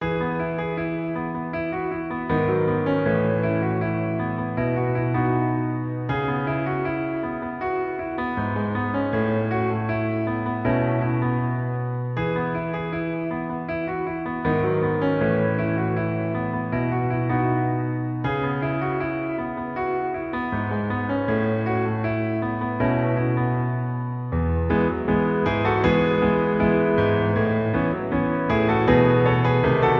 • 🎹 Instrument: Piano Solo
• 🎼 Key: E Major
• 🎶 Genre: Pop
Arranged in E Major